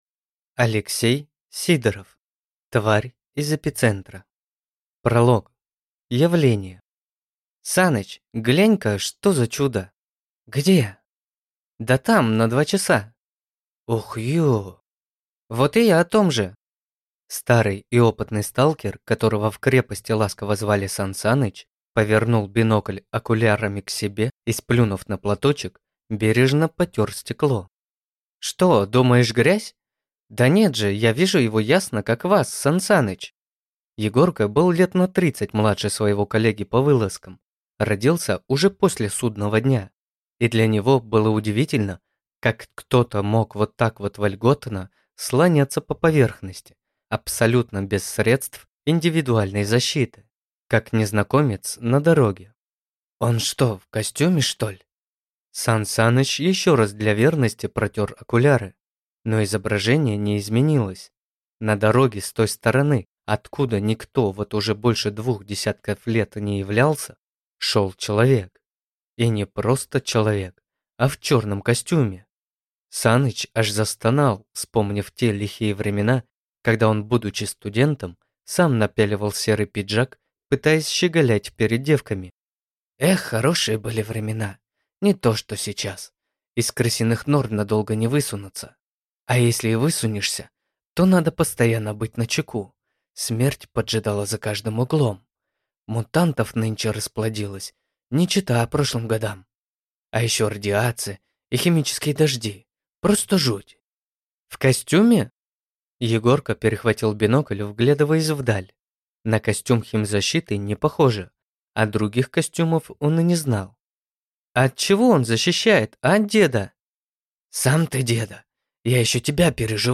Аудиокнига Тварь из эпицентра | Библиотека аудиокниг